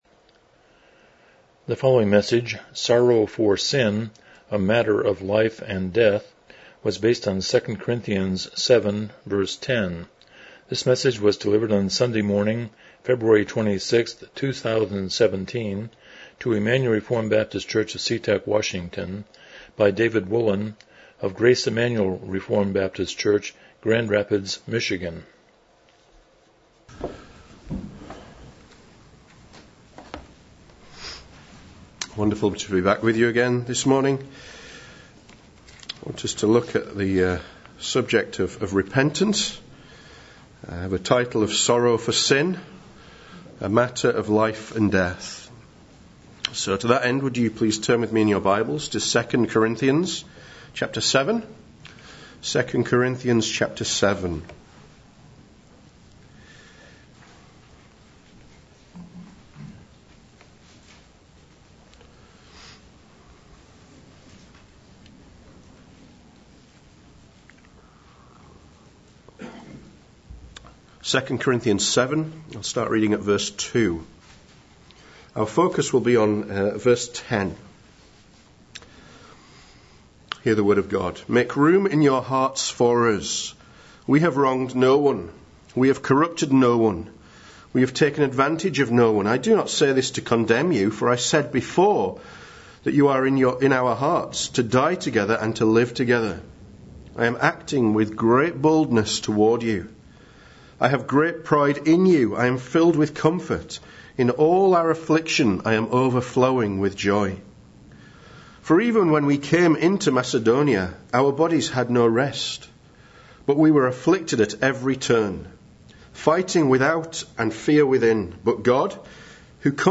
Passage: 2 Corinthians 7:10 Service Type: Morning Worship